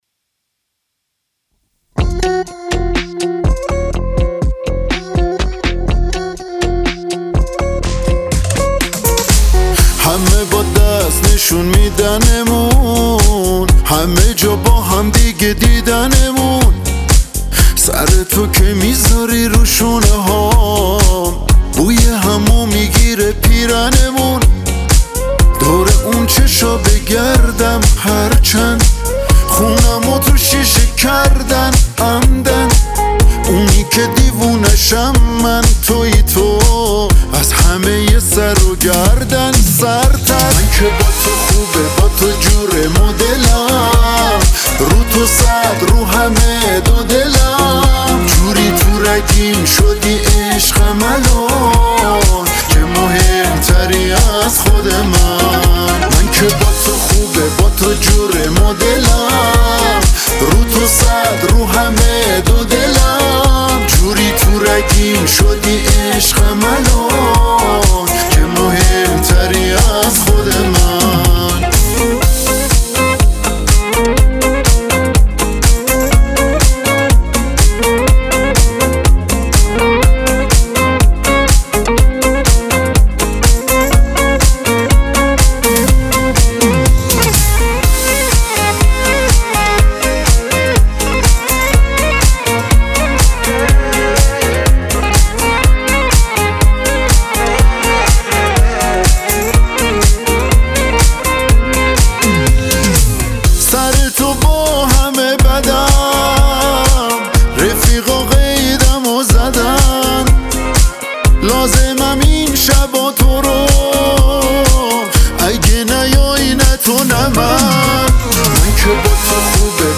پاپ
آهنگ با صدای زن
اهنگ ایرانی